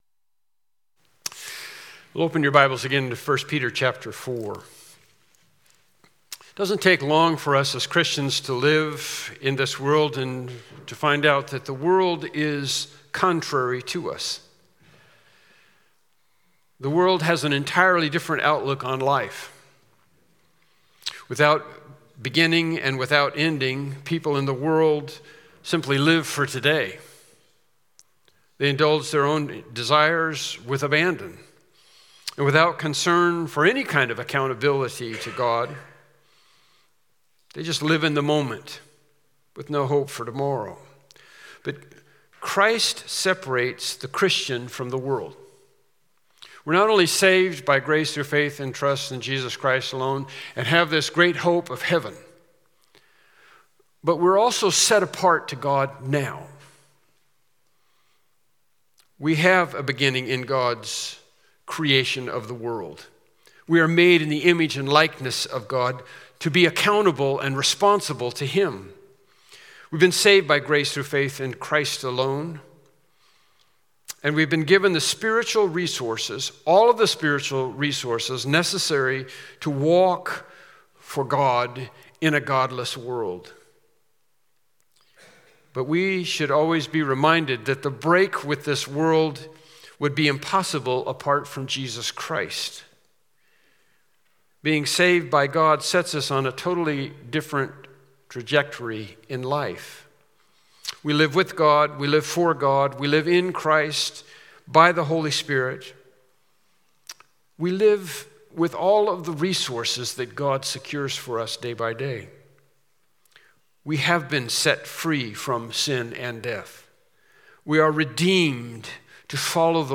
1 Peter 4:1-3 Service Type: Morning Worship Service « Doing Good in the Context of Suffering The People of the Millennium